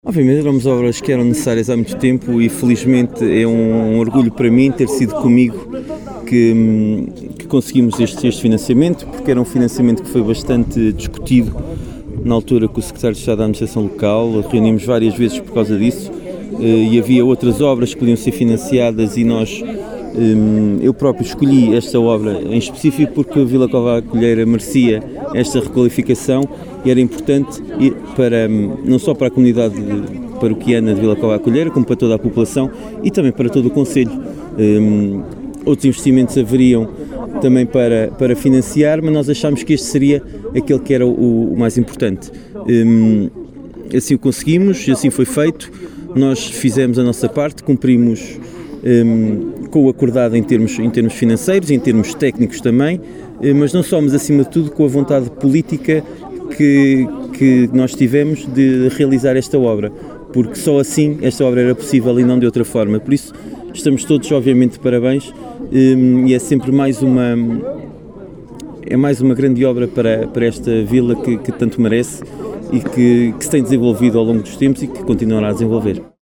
Paulo Marques, Presidente do Município de Vila Nova de Paiva, disse que é com grande orgulho que vê estas obras de beneficiação realizadas, “Vila Cova à Coelheira merecia esta requalificação…”.